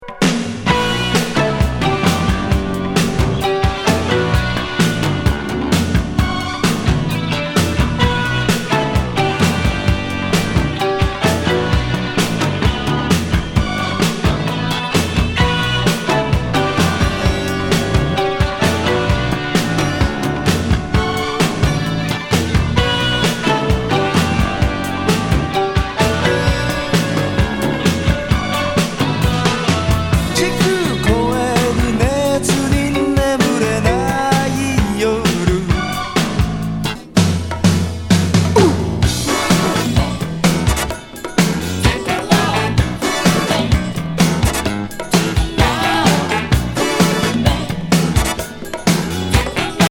ファンキーロック